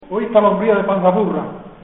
Zafarraya (Granada) Icono con lupa
Secciones - Biblioteca de Voces - Cultura oral